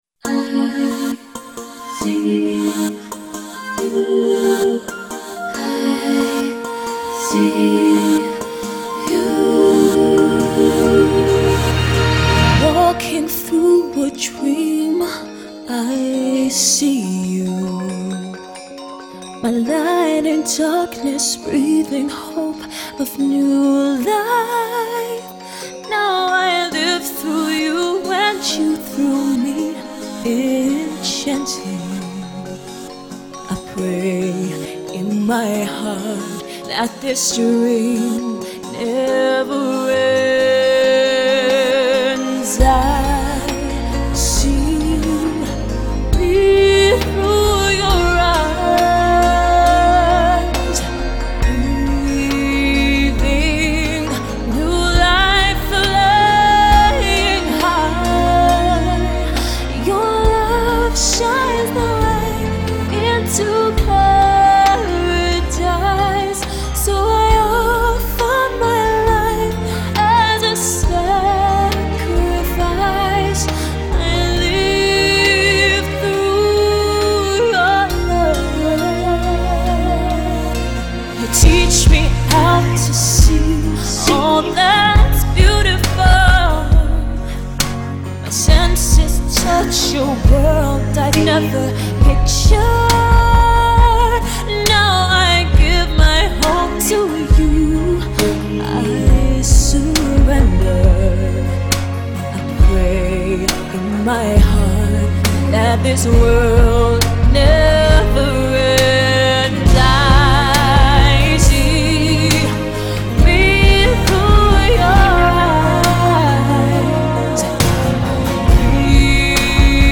Главная » Файлы » Музыка MP3 » SoundTrack